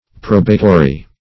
probatory - definition of probatory - synonyms, pronunciation, spelling from Free Dictionary
Probatory \Pro"ba*to*ry\, a. [Cf. F. probatoire.]